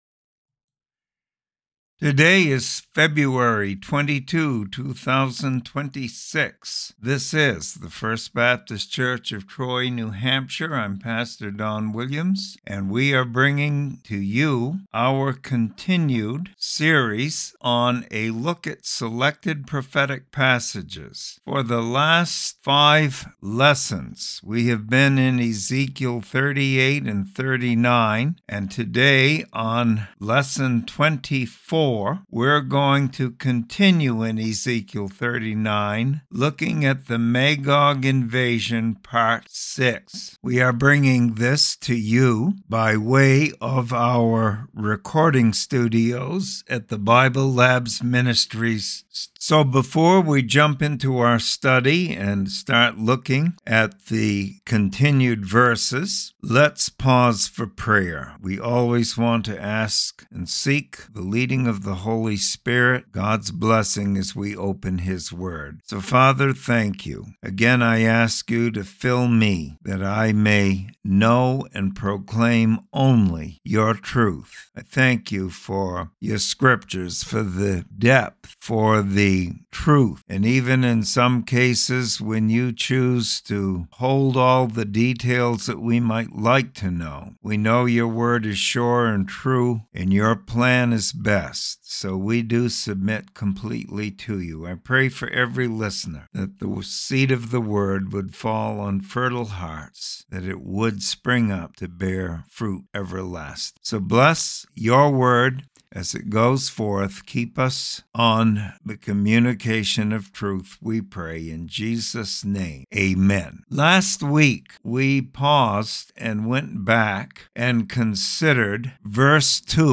Bible Study and Commentary on Ezekiel 39:8-16 Bible Prophecy Regarding the Battle of Gog and Magog